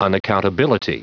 Prononciation du mot unaccountability en anglais (fichier audio)
Prononciation du mot : unaccountability